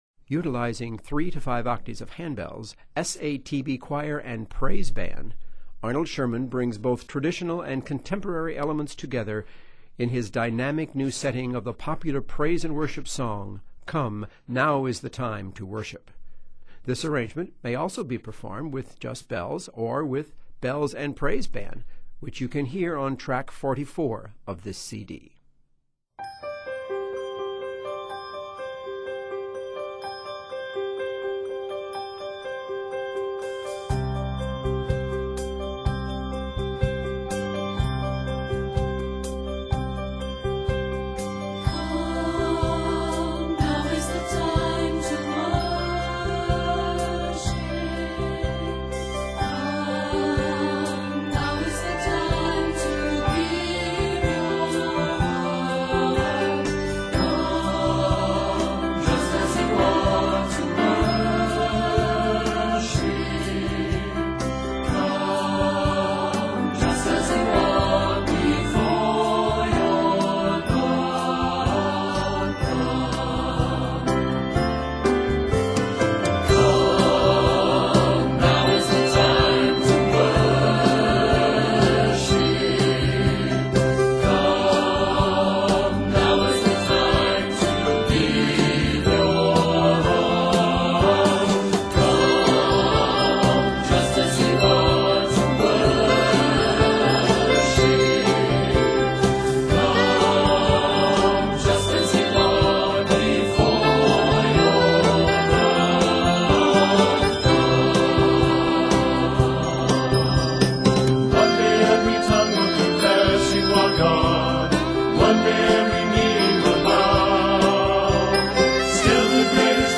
Utilizing 3-5 octave handbells, SATB choir and praise band
Set in C Major and D Major, this work is 109 measures.